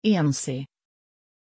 Pronunciation of Shenzi
pronunciation-shenzi-sv.mp3